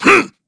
Shakmeh-Vox_Attack1_kr.wav